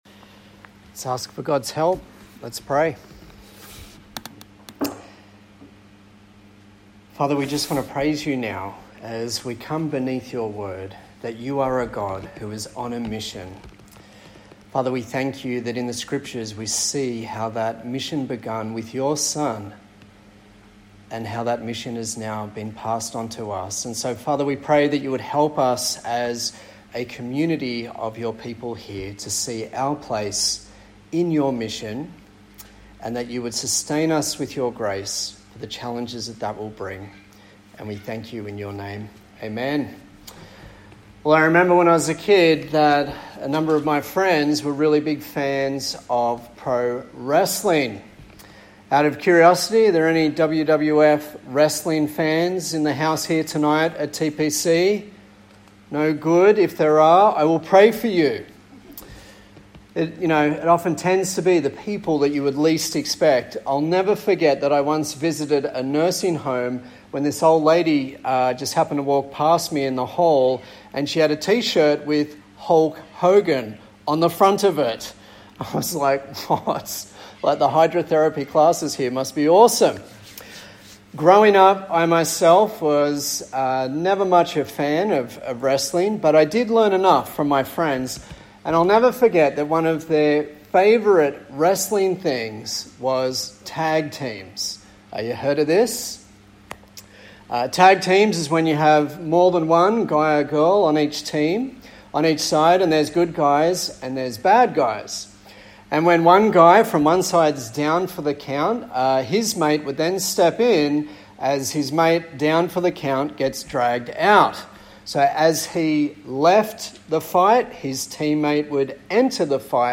Acts Passage: Acts 8:4-25 Service Type: Sunday Morning A sermon in the series on the book of Acts